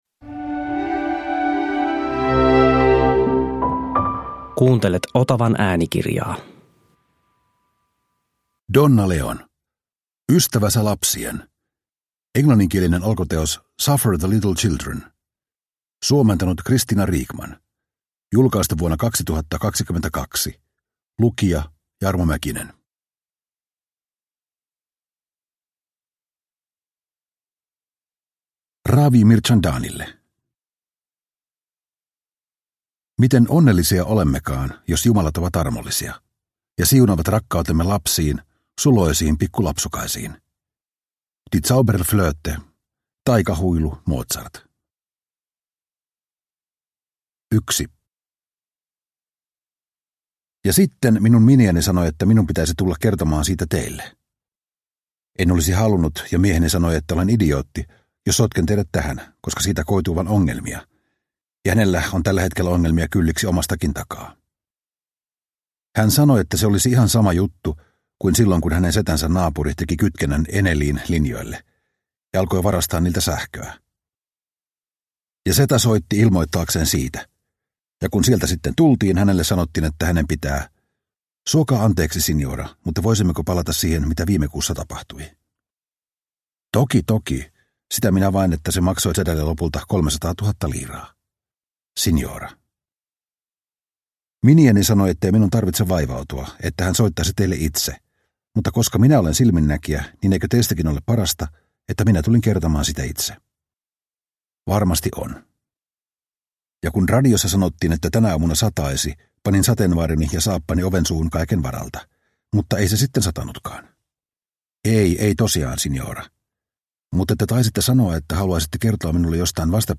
Ystävä sä lapsien – Ljudbok